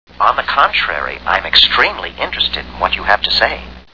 KITT Talks KITT Talks KITT Talks
knight_rider_interested.wav